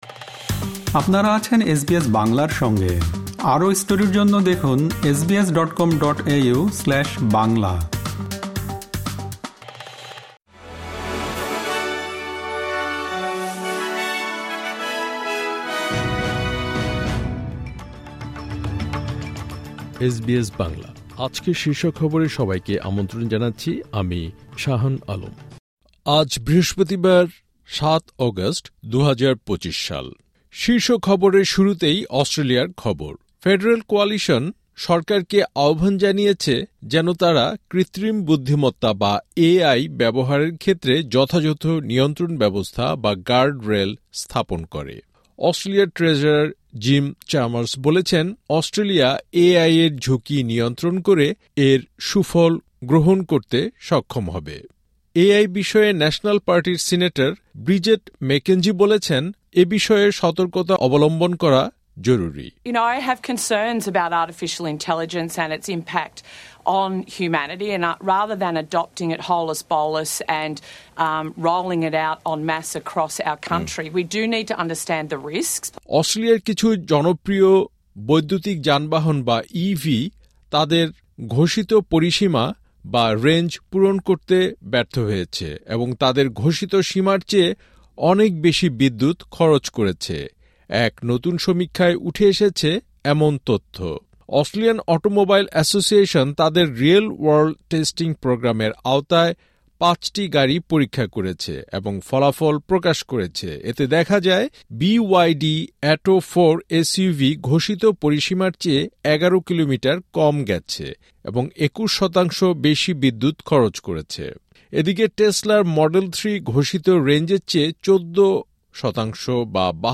এসবিএস বাংলা শীর্ষ খবর: ৭ অগাস্ট, ২০২৫